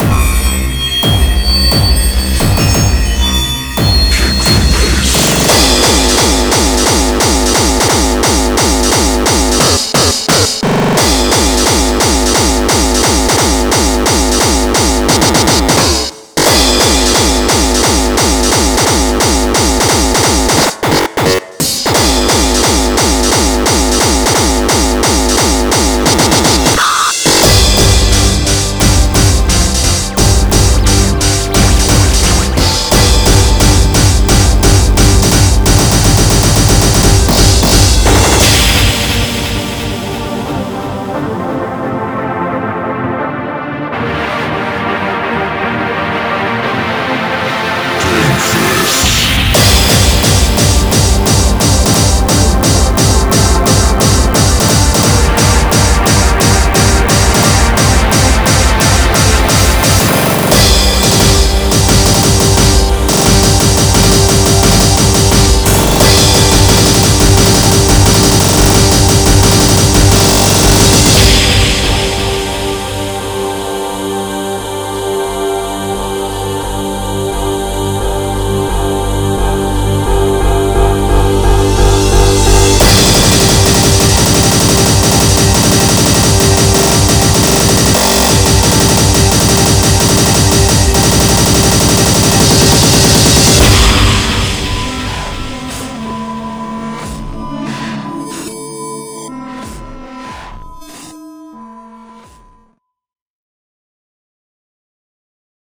BPM175
Audio QualityPerfect (High Quality)
Comentarios[HARD INDUSTRIAL]